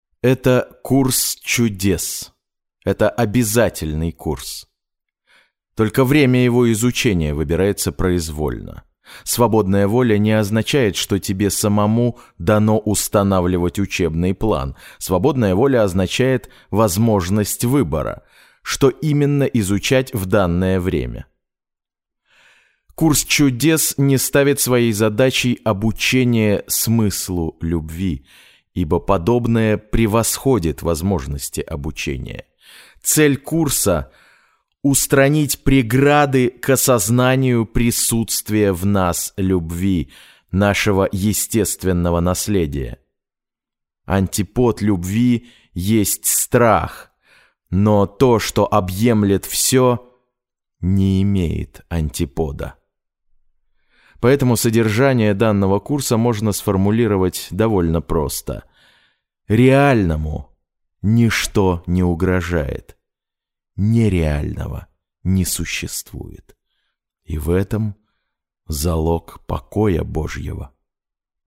Некоторое время назад мне начали заказывать женские романы эротического содержания и я обнаружил, что мой голос имеет особенный успех у женщин :) По манере стараюсь держаться между дикторским и актёрским стилем, если нет других указаний от заказчика.
Marshall Electronics MXL 909 M-Audio Firiwire Solo